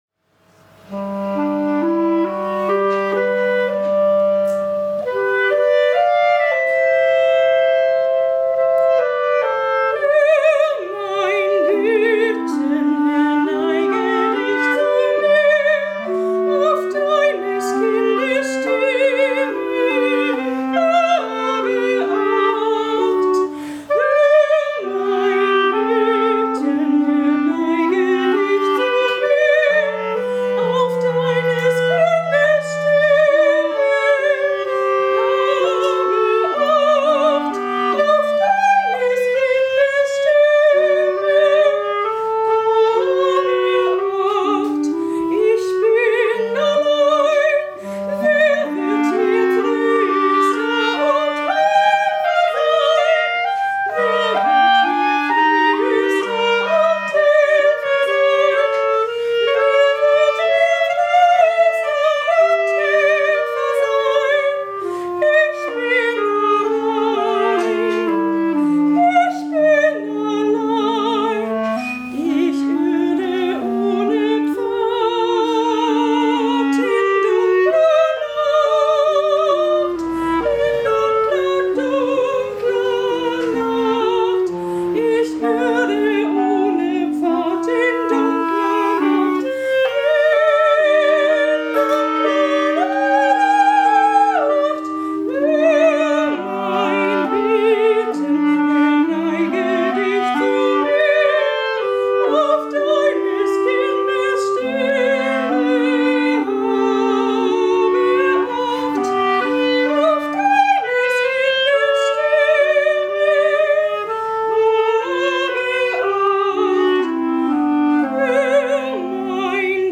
Diese Folge wurde am 17. Oktober live im Eiscafé Giannone aufgenommen.